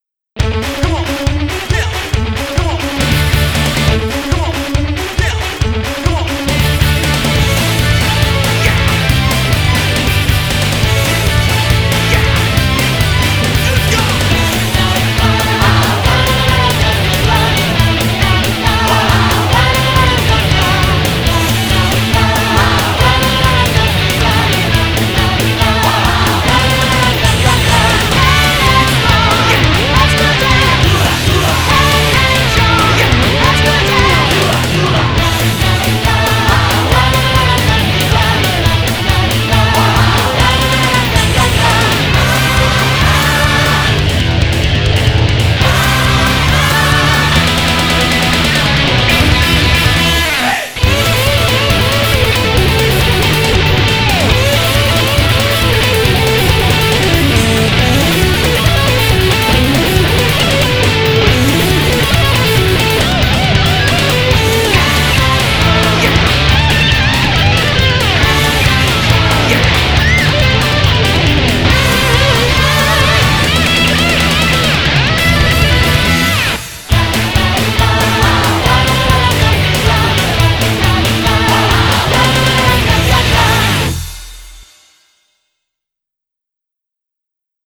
BPM276
Audio QualityPerfect (High Quality)
Comentarios[HARD COUNTRY 2]